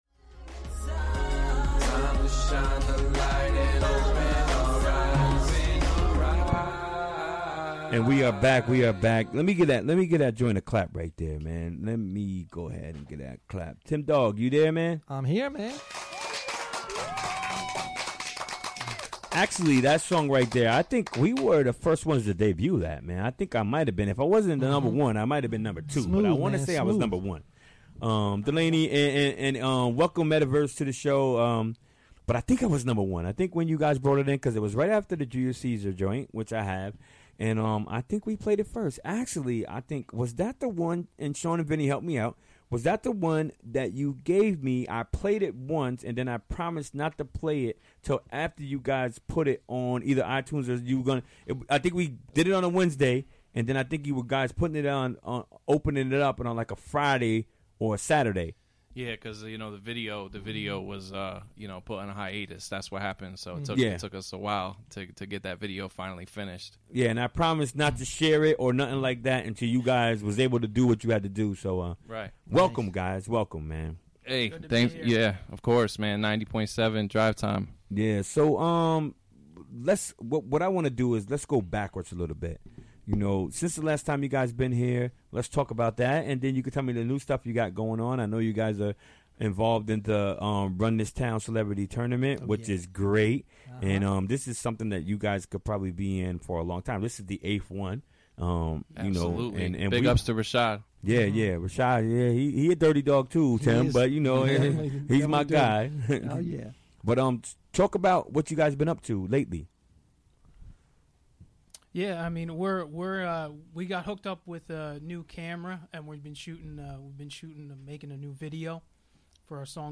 Recorded during the WGXC Afternoon Show Wednesday, March 14, 2018.